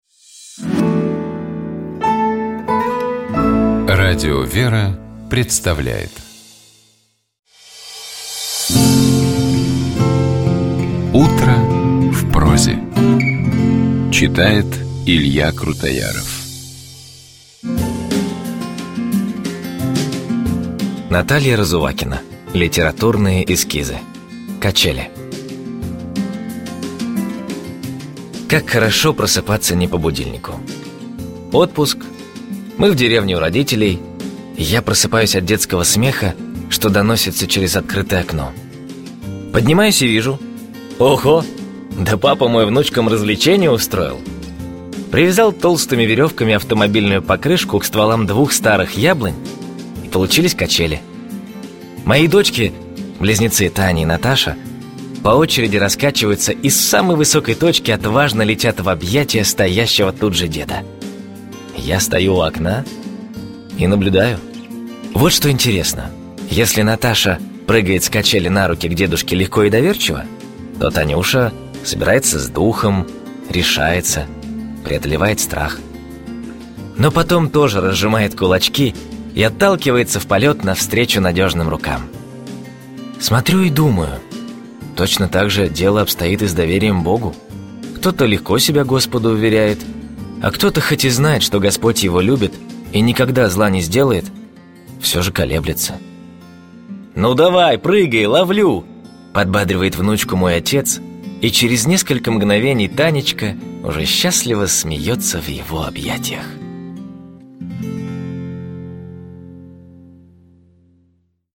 протоиерей